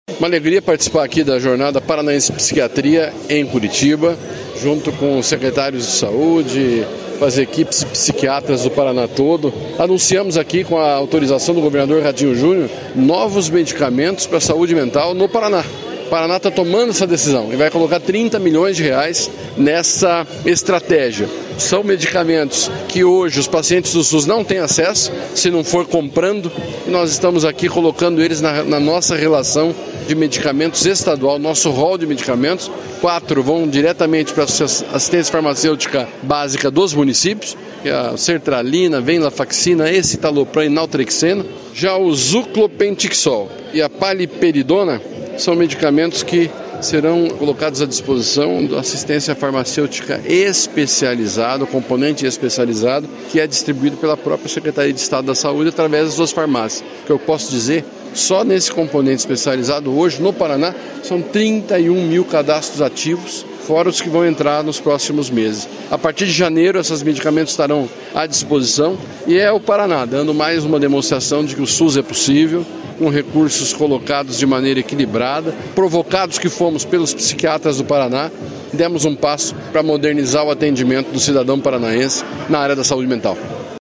Sonora do secretário da Saúde, Beto Preto, sobre a ampliação da oferta de medicamentos gratuitos para saúde mental